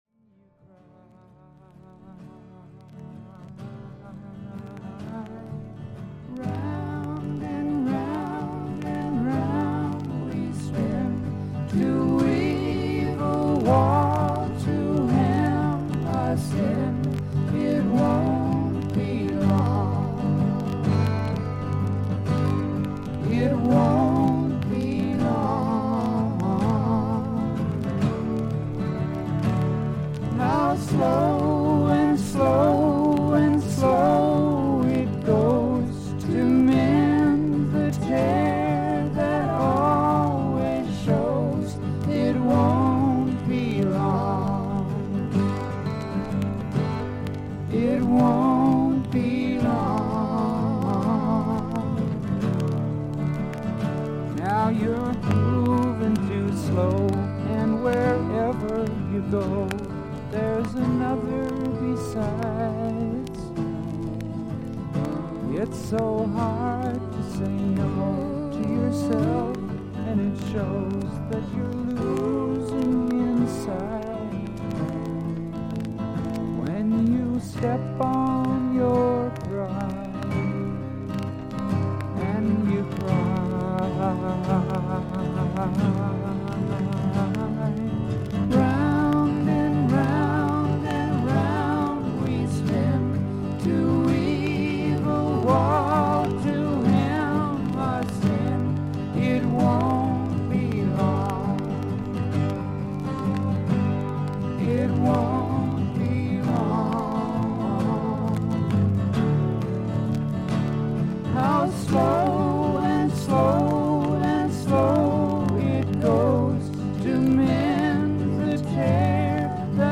アメリカ盤 / 12インチ LP レコード / ステレオ盤
A3中盤からA4中盤まで2cmのキズ、少々周回ノイズあり。